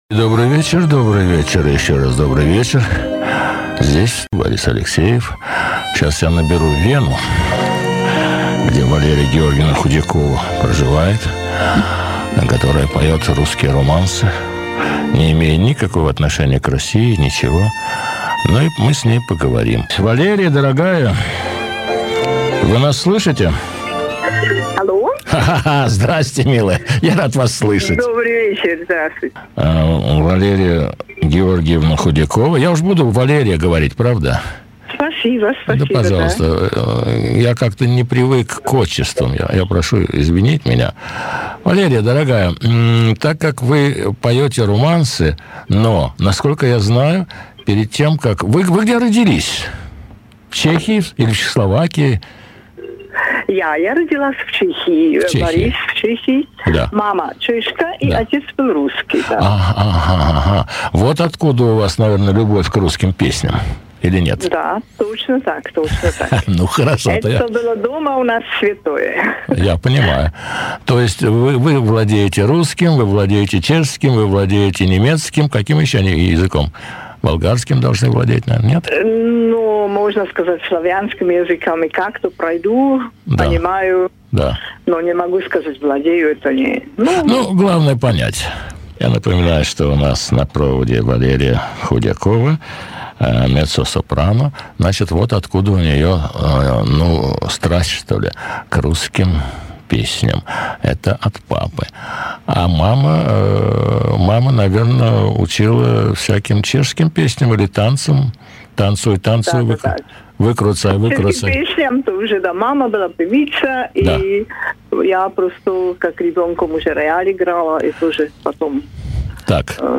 Интервью радиоведущего